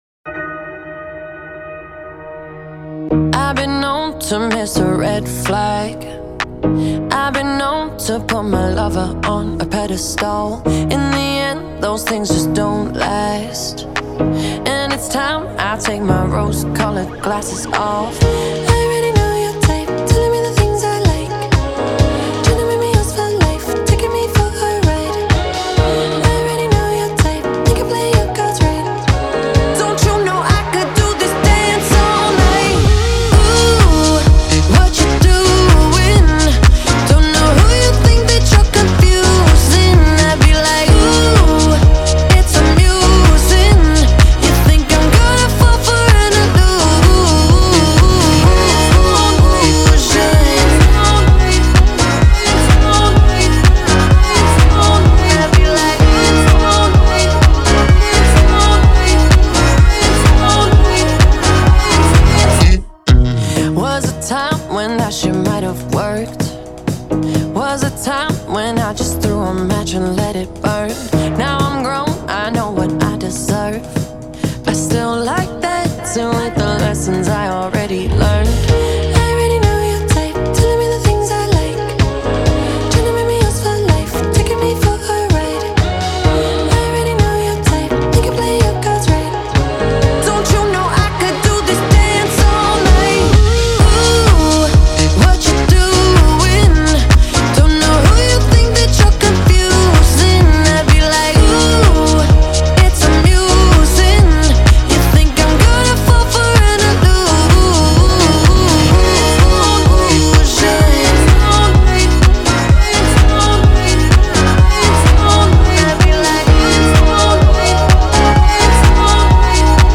BPM127-127
Audio QualityPerfect (High Quality)
Pop Disco song for StepMania, ITGmania, Project Outfox